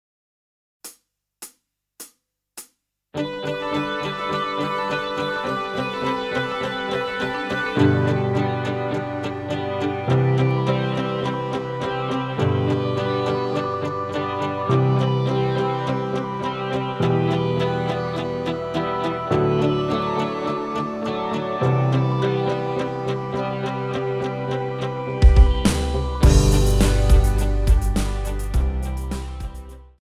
Žánr: Pop
BPM: 104
Key: G